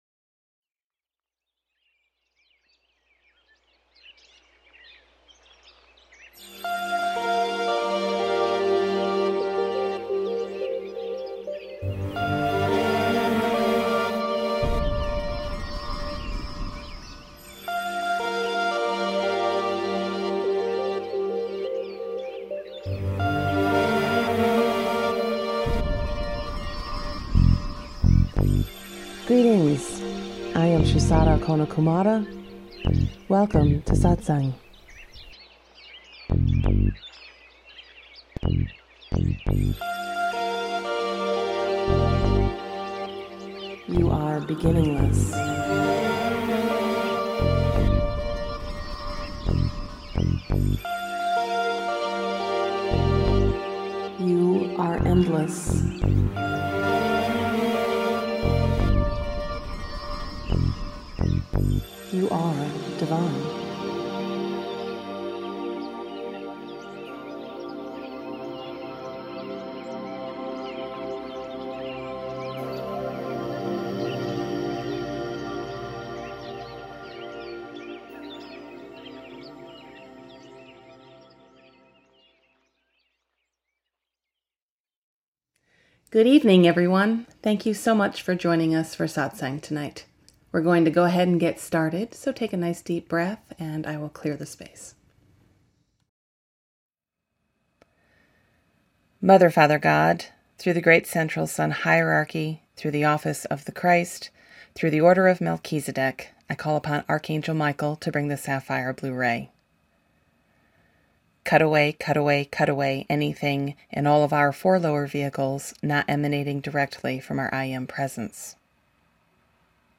Audio Satsang Session from October 10, 2017